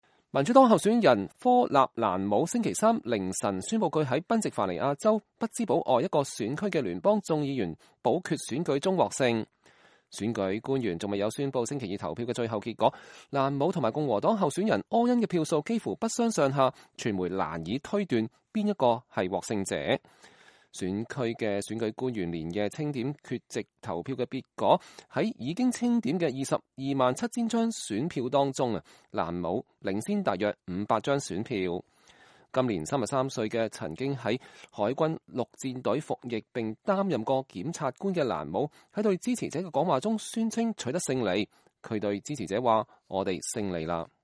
民主黨候選人科納蘭姆在對支持者的講話中宣稱取得勝選